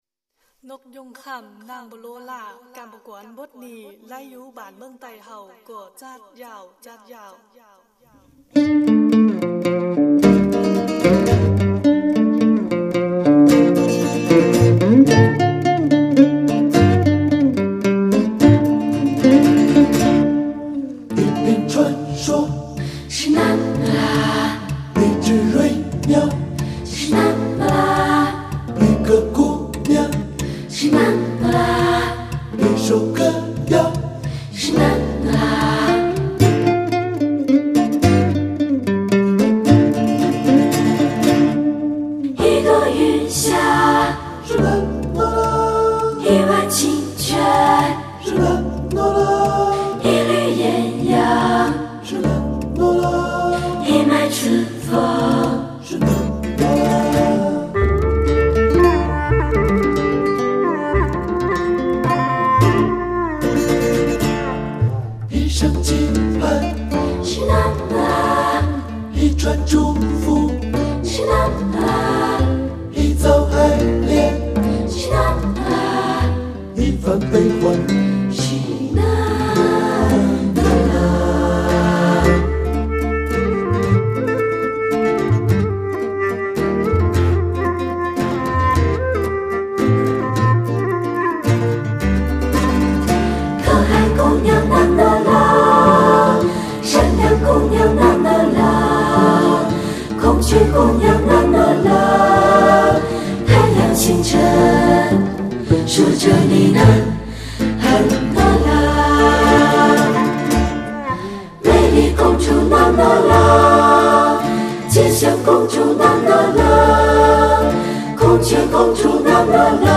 原声乐器+电子MIDI。